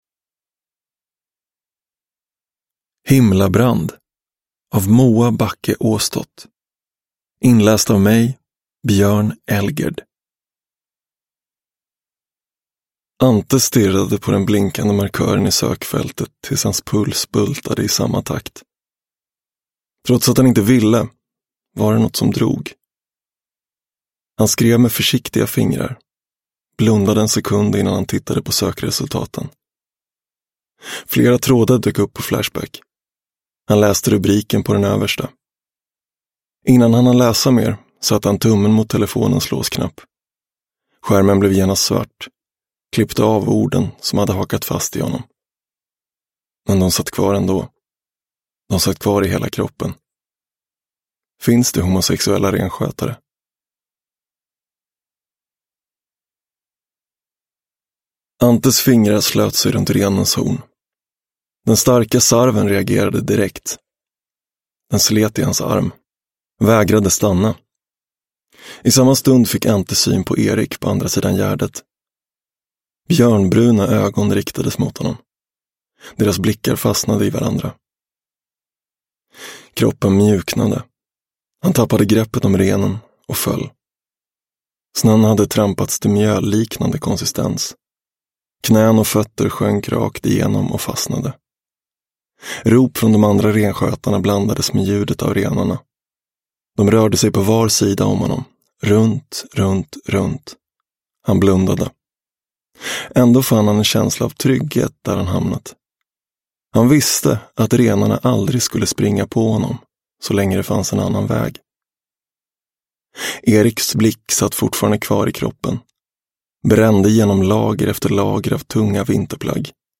Himlabrand – Ljudbok – Laddas ner